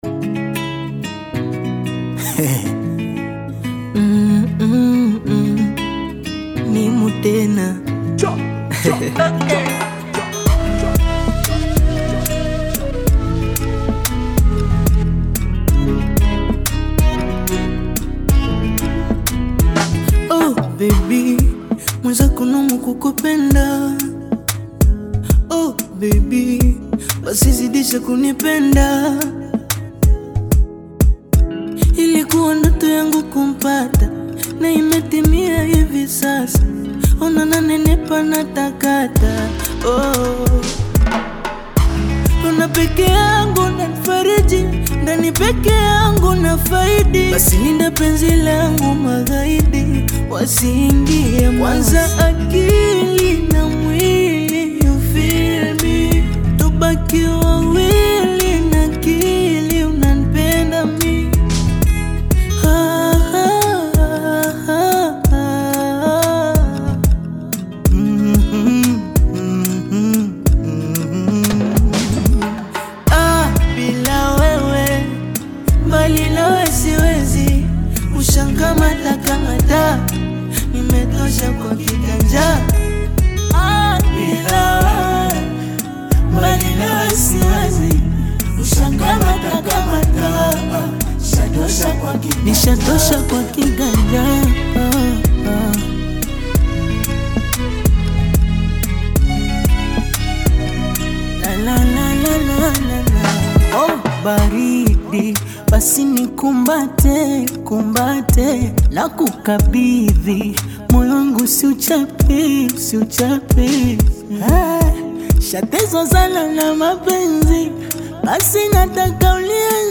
Tanzanian Bongo Flava artist, singer and songwriter
love song
Bongo Flava song